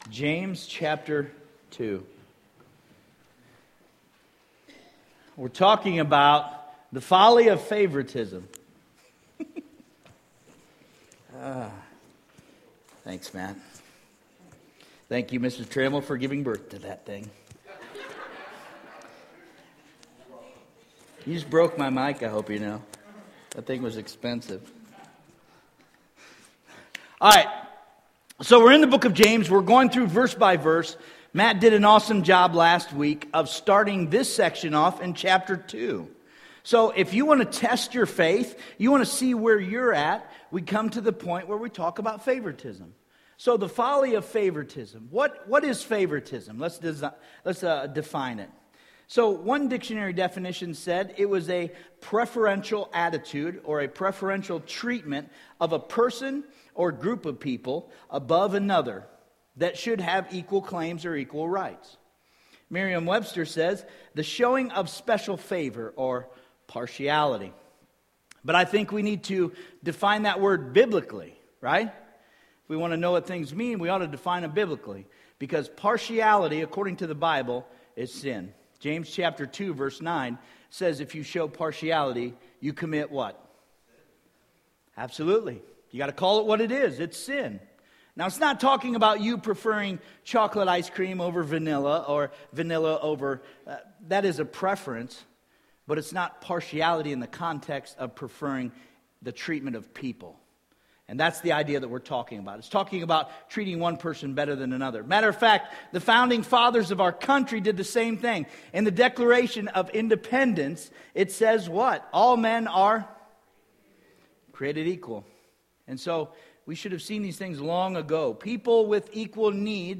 Sermons Archive - Page 41 of 52 -